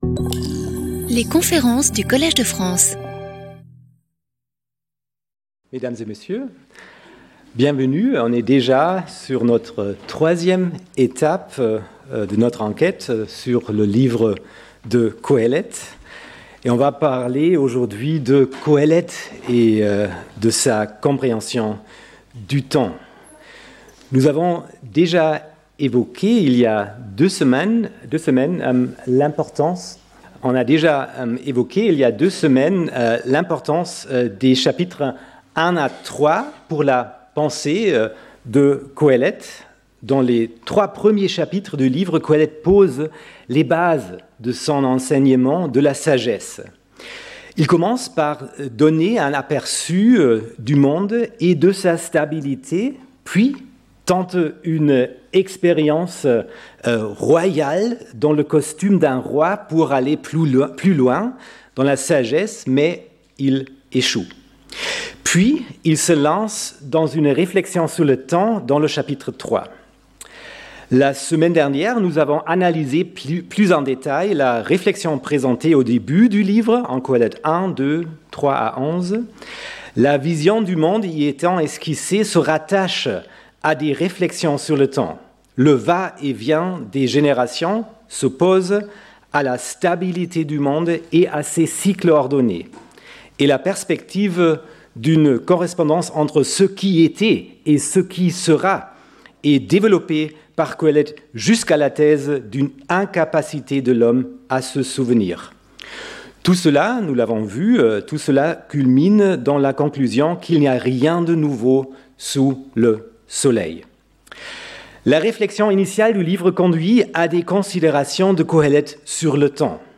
Guest lecturer